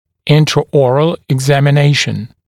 [ˌɪntrə»ɔːrəl ɪgˌzæmɪ’neɪʃn] [ˌинтрэ’о:рэл игˌзэми’нэйшн] обследование полости рта, осмотр полости рта